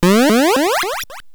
teleport.wav